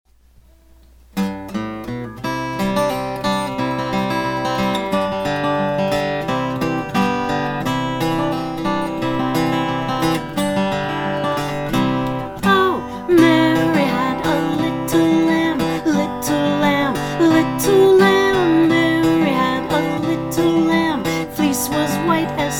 Vocal Song Downloads